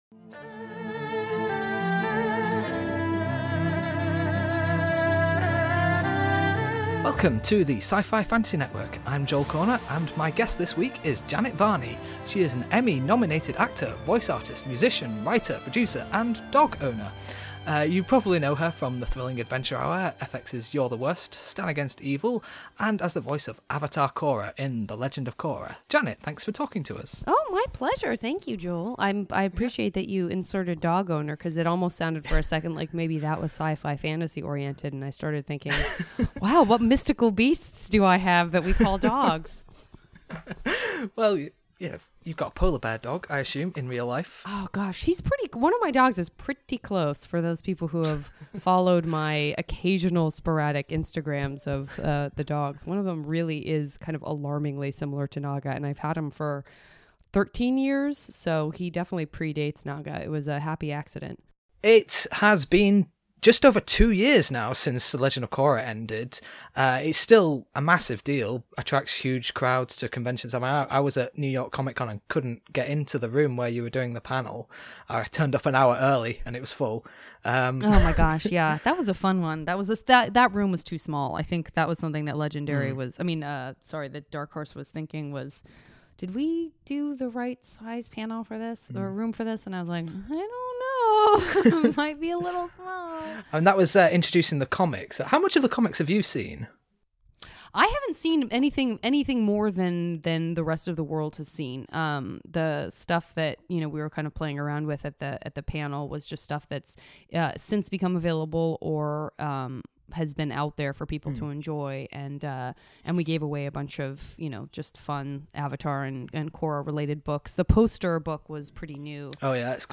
It was my tremendous pleasure to interview Janet Varney!
janet-varney-interview-audio.mp3